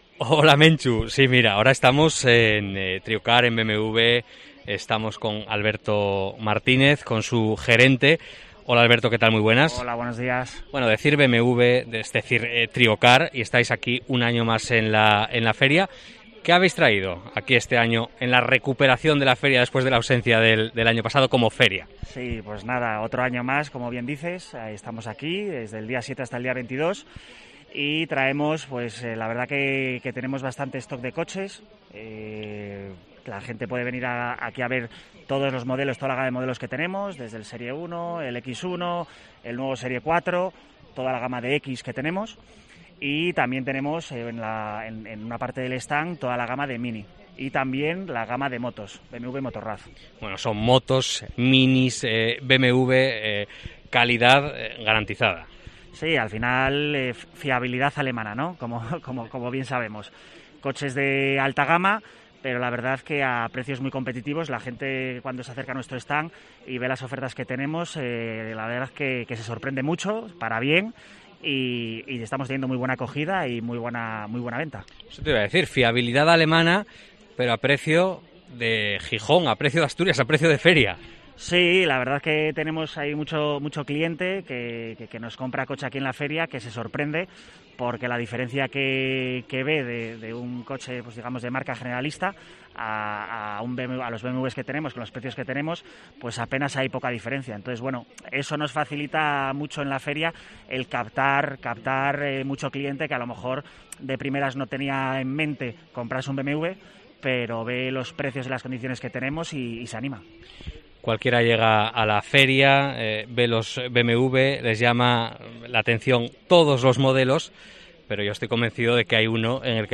COPE EN LA FIDMA
Entrevista